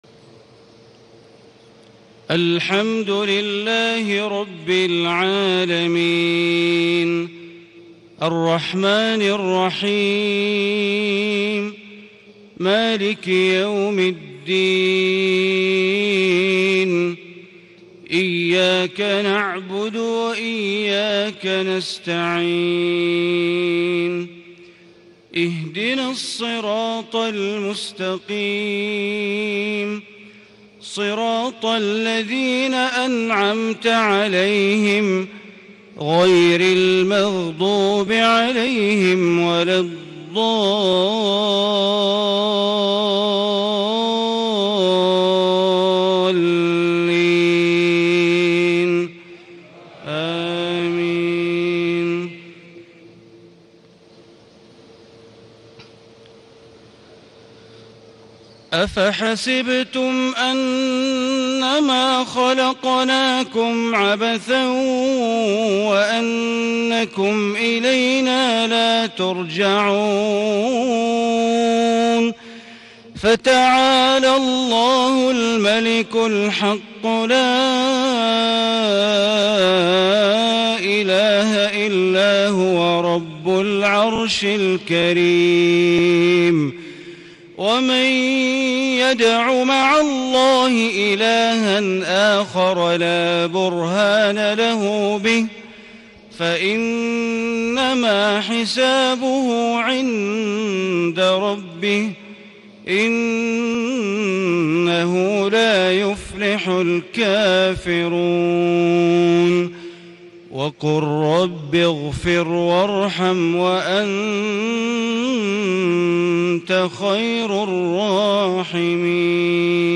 صلاة المغرب 1 ذوالقعدة 1437هـ خواتيم سورتي المؤمنون 115-118 و القيامة 36-40 > 1437 🕋 > الفروض - تلاوات الحرمين